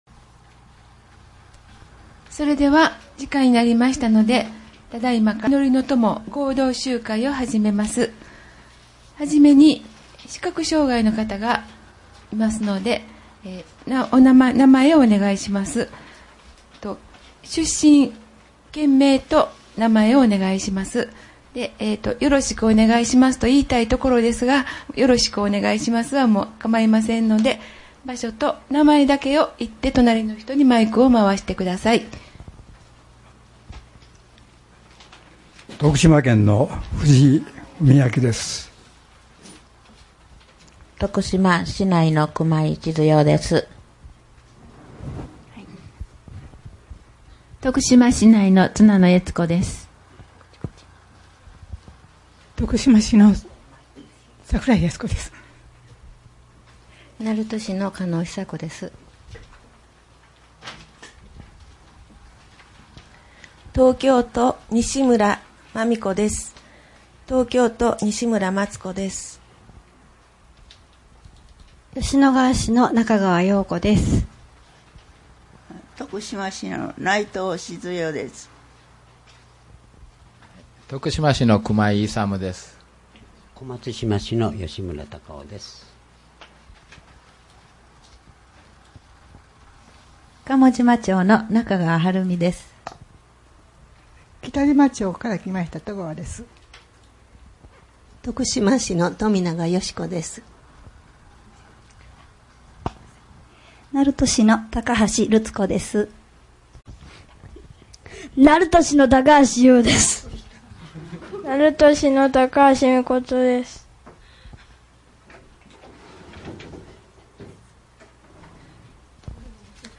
開会メッセージ 「御国を来たらせたまえ」